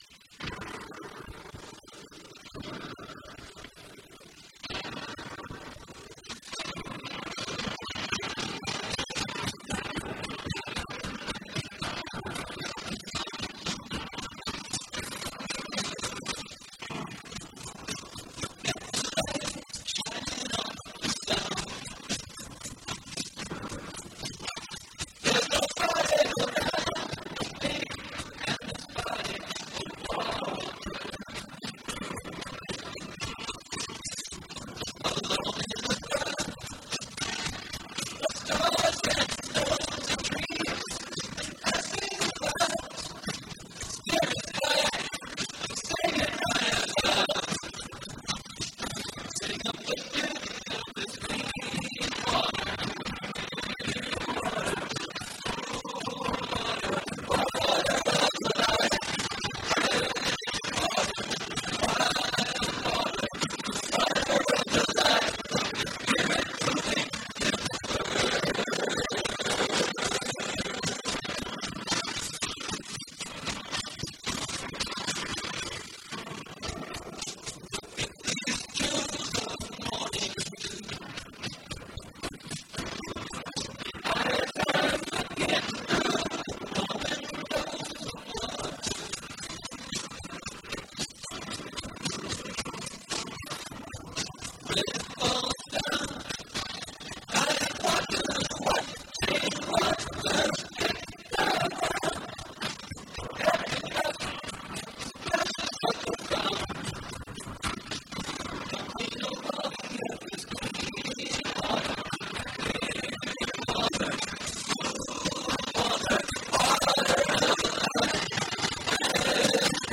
The quality of the one copy I have is very bad.